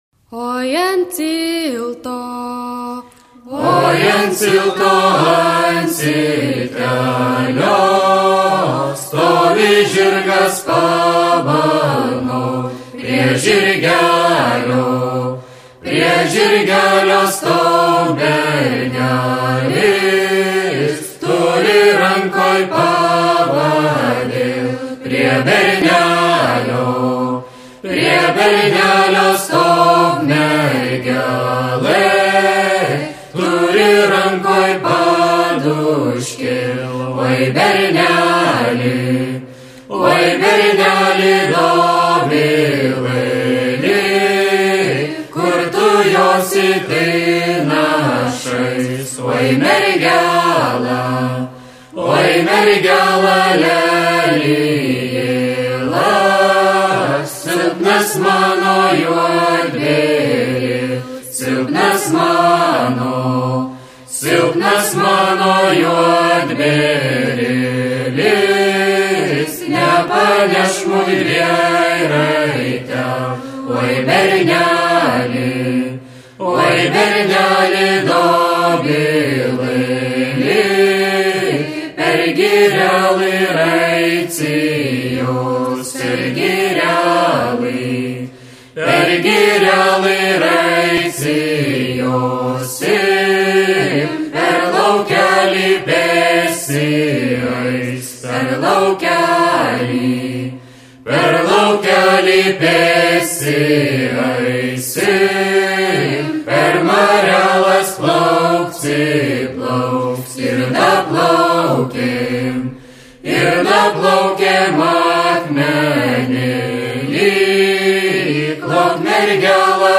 ansamblis